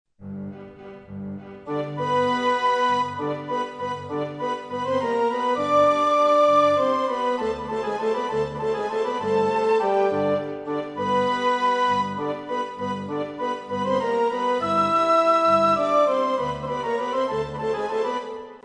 La prima volta in un andamento più Allegro:
La durata effettiva dei suoni è invece diversa nelle due versioni: più breve nella prima perché in andamento allegro, più lunga nella seconda, perché in andamento lento.